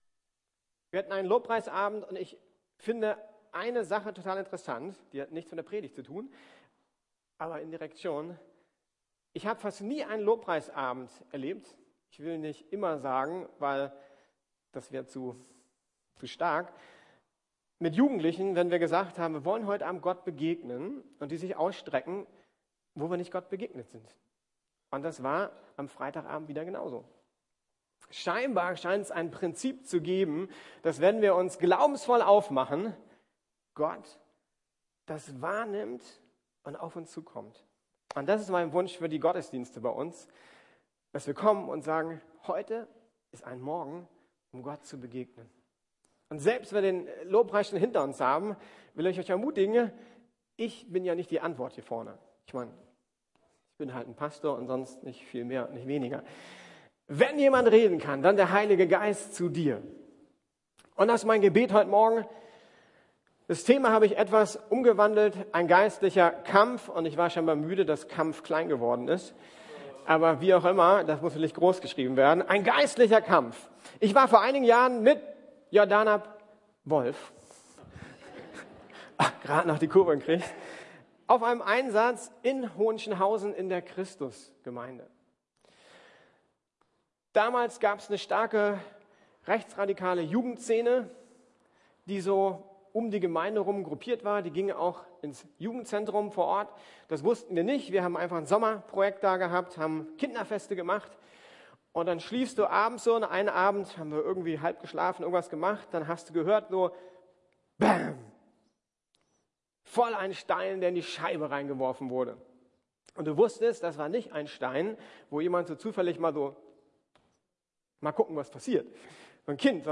Siegreich im Denken ~ Predigten der LUKAS GEMEINDE Podcast